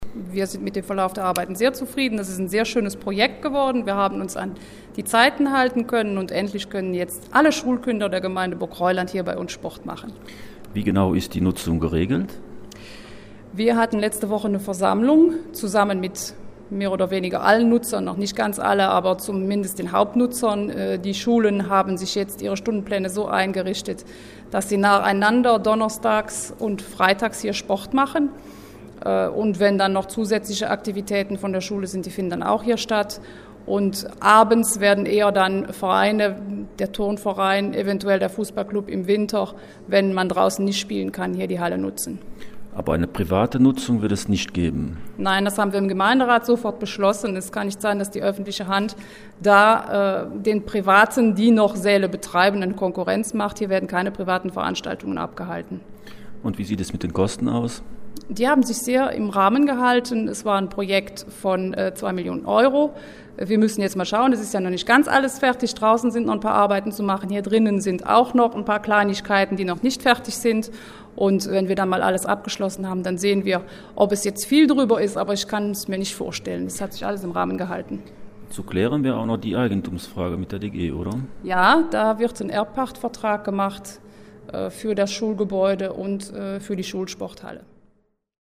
Entsprechend zufrieden ist Bürgermeisterin Marion Dhur: